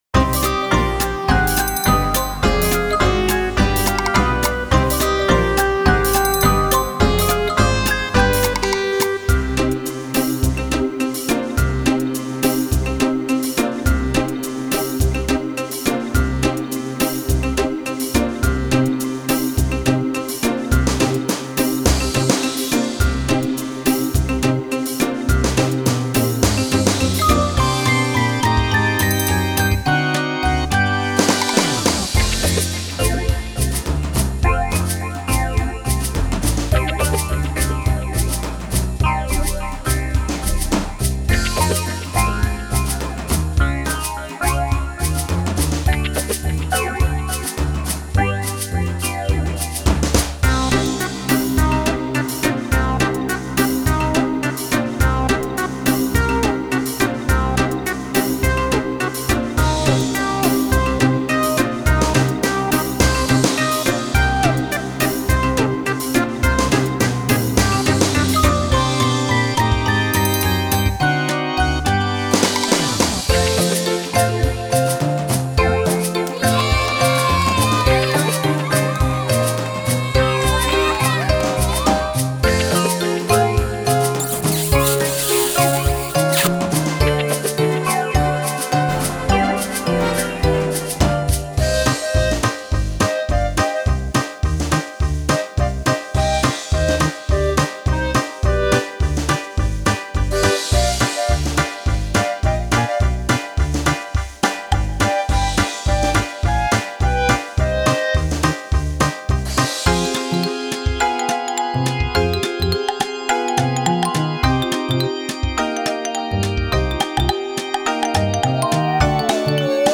SchouderCom - Zonder zang
Lied-1-Een--twee--drie-instrumentaal-.mp3